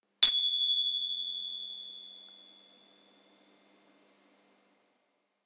Golpe de crótalos mantenido
percusión
continuo
crótalo
golpe